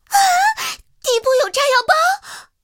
KV-2中破语音.OGG